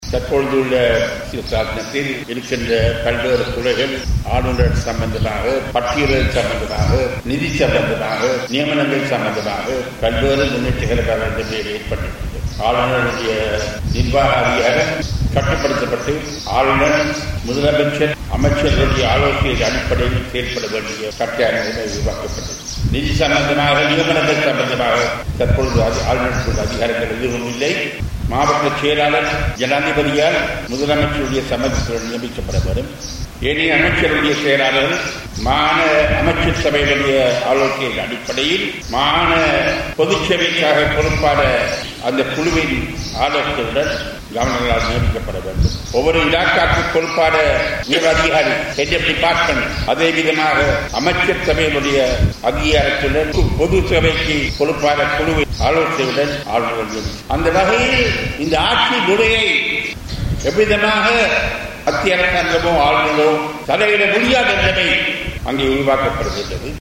மன்னாரில் நேற்று இடம்பெற்ற நிகழ்வொன்றில் கலந்துகொண்டு உரையாற்றும்போது அவர் இதனை தெரிவத்தார்.